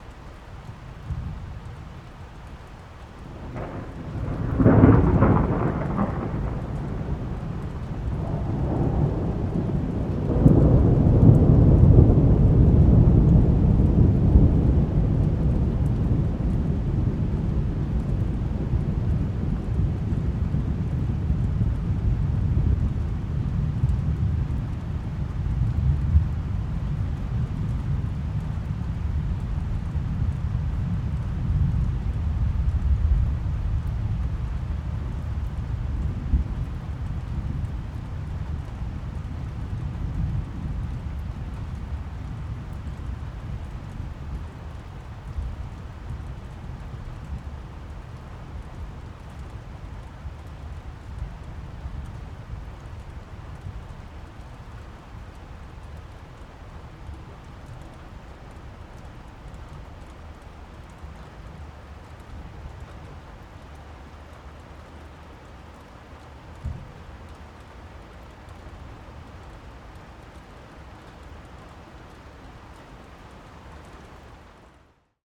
ambient-thunder-with-subtle-rain-short-dry-mono.ogg